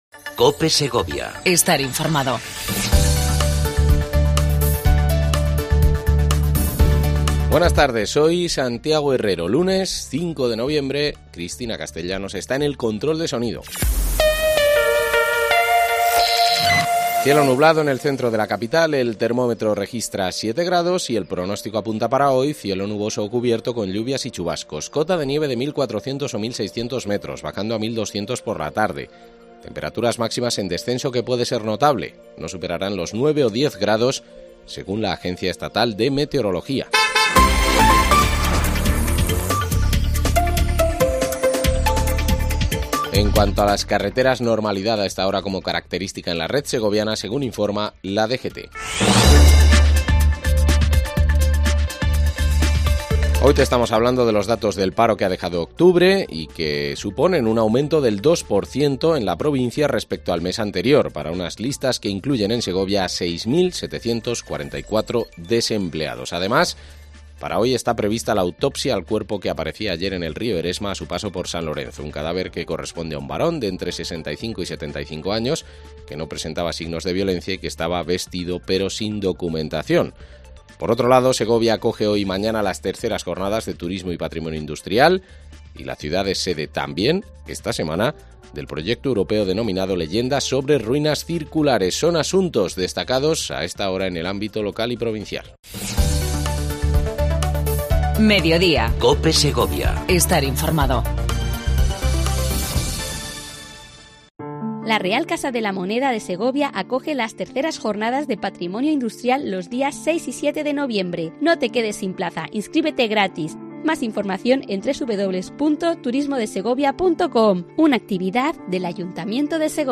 AUDIO: Entrevista a Basilio del Olmo, Diputado Provincial responsable del Área de Acción Territorial